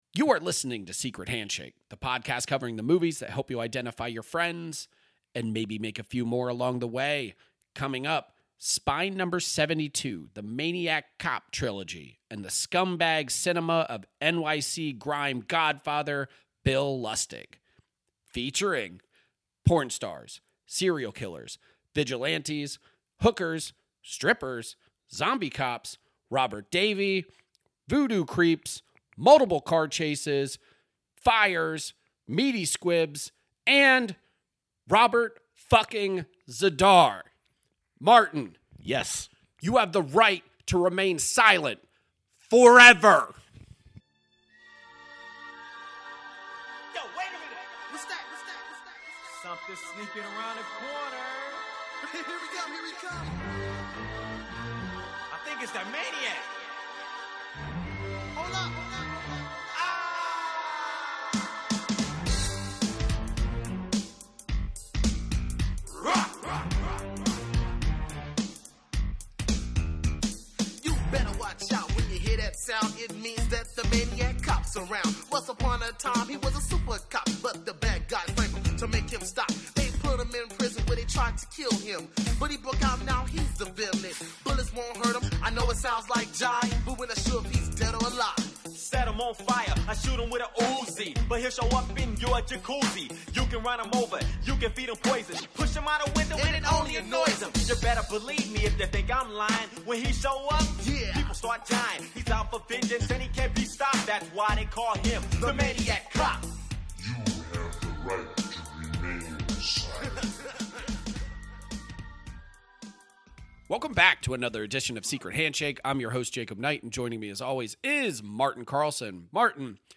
1 BONUS: Screen Rot Live - Christmas Special 1:15:22